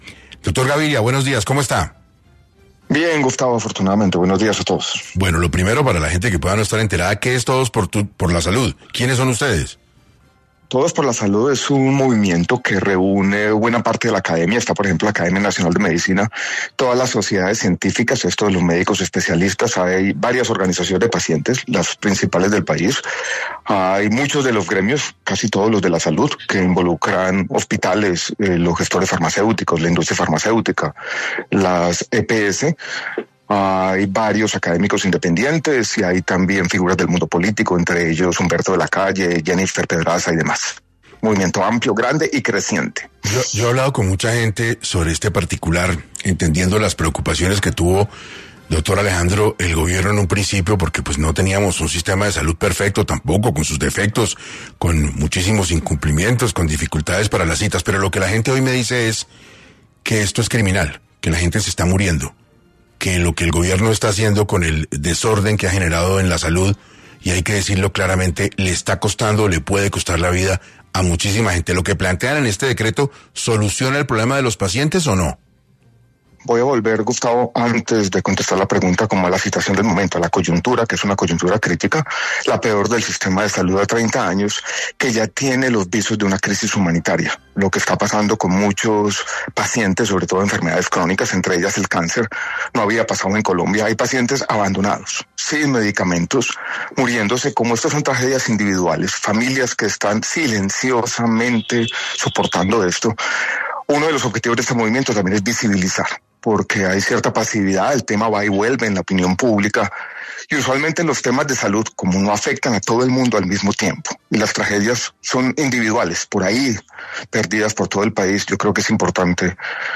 Uno de sus integrantes, Alejandro Gaviria, exministro de salud, habló para 6AM y advirtió por qué este decreto es un riesgo para la salud.